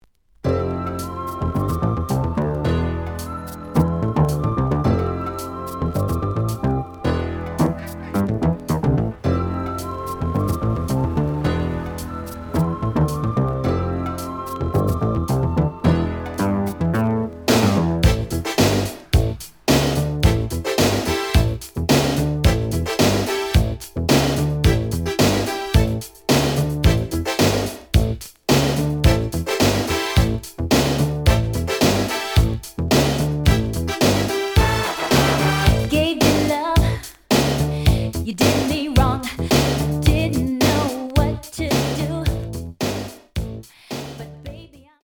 The audio sample is recorded from the actual item.
●Format: 7 inch
●Genre: Hip Hop / R&B